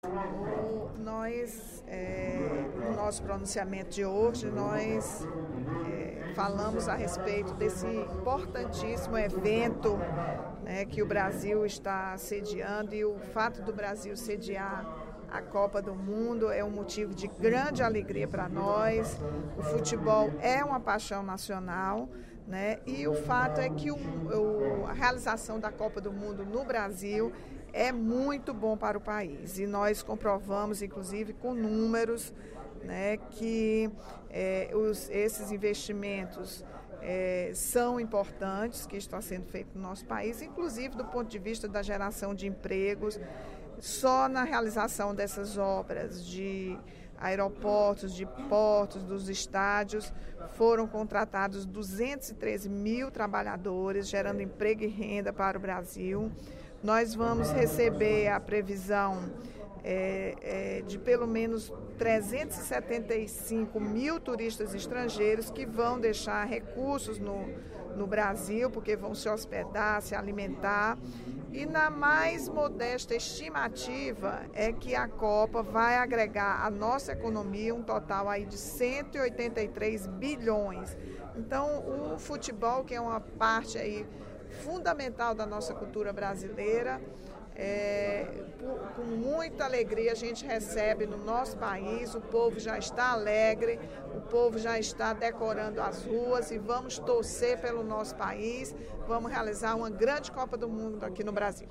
No primeiro expediente da sessão plenária desta quarta-feira (11/06), a deputada Rachel Marques, líder do Partido dos Trabalhadores na Assembleia Legislativa, disse que a Copa do Mundo no Brasil é um momento importante para o País, sendo motivo de alegria e orgulho para seu povo.